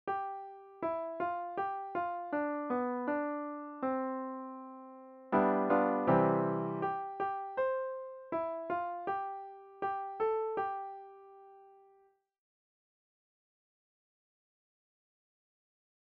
This first melody, "Billy Boy" goes way way back in Americana. Here the written pick up notes become the turnaround melody line supported by V7.
The turnaround chord is the G7 right in the middle of the graphic. The common V7 to I cadential motion.